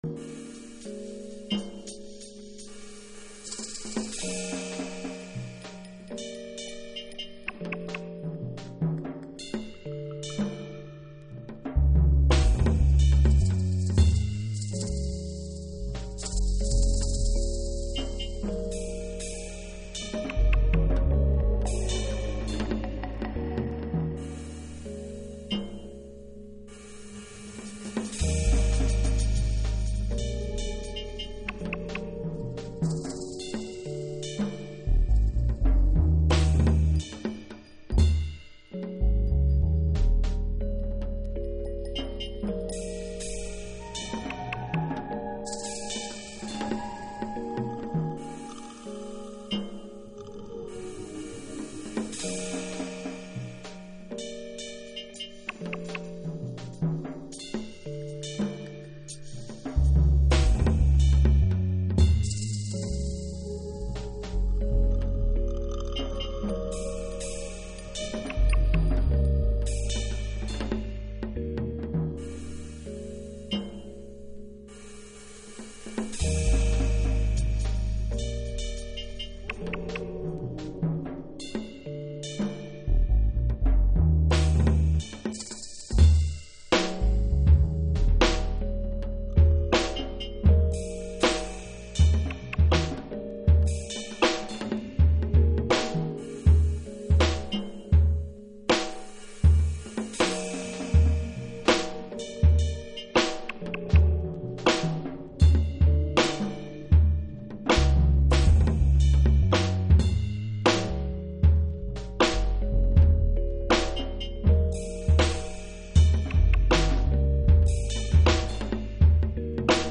TOP > Future Jazz / Broken beats > VARIOUS